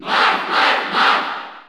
Crowd cheers (SSBU) You cannot overwrite this file.
Marth_Cheer_Spanish_NTSC_SSB4_SSBU.ogg